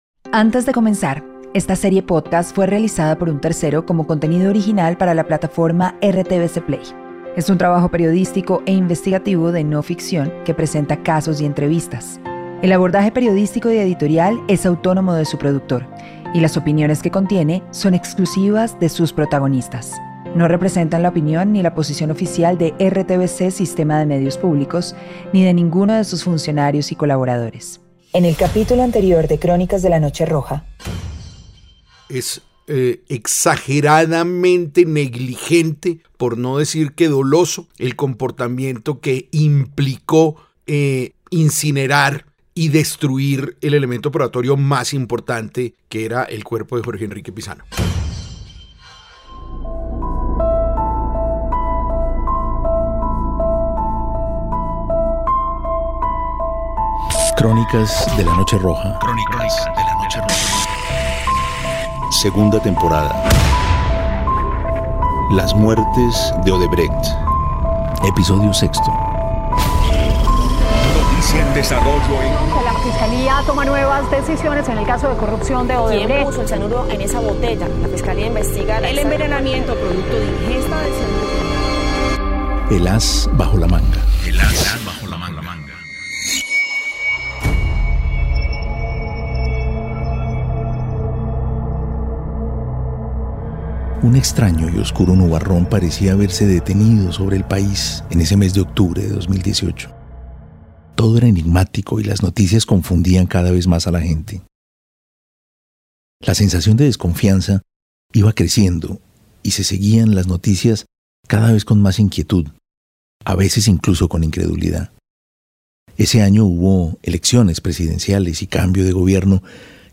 Podcast investigación criminal.